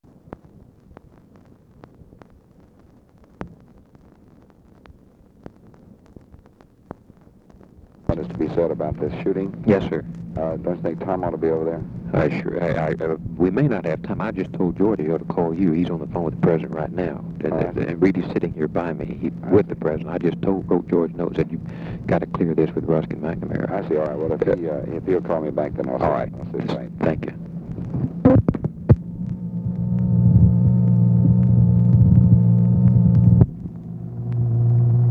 Conversation with DEAN RUSK and BILL MOYERS, May 20, 1965
Secret White House Tapes